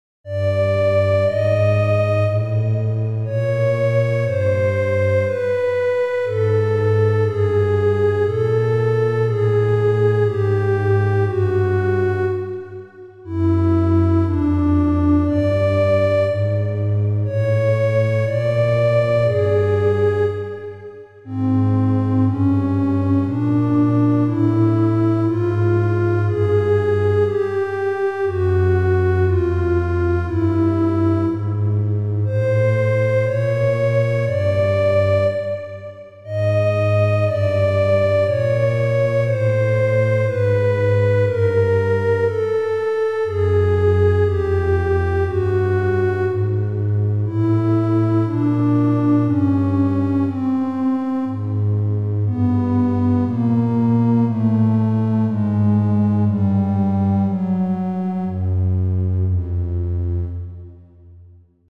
ホラーで不気味たことがおこったとか怪談話のイメージ。
BPM60
ホラー